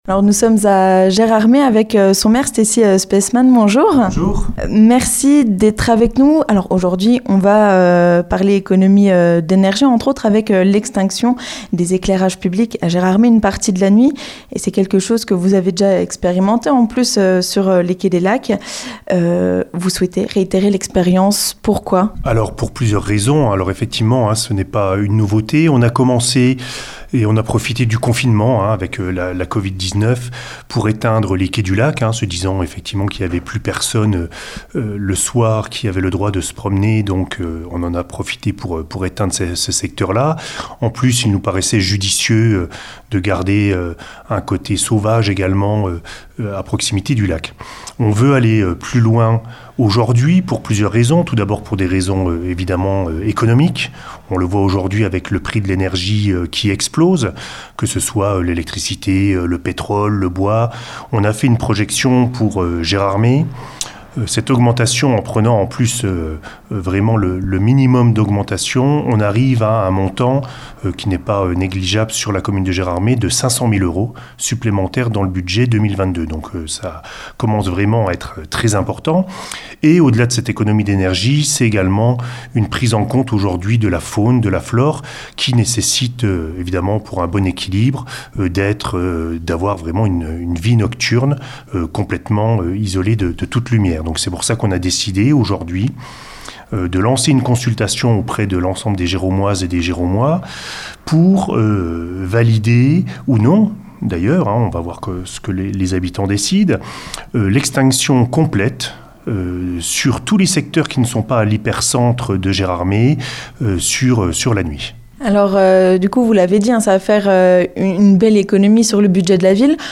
Nous sommes allés à la rencontre de Stessy Speissmann, le Maire de Gérardmer pour lui poser quelques questions à ce sujet !